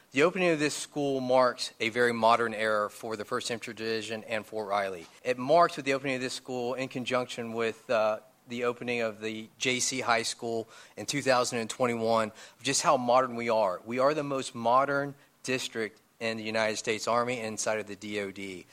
spoke at Friday’s ceremony about the state of school facilities on Fort Riley and in USD 475.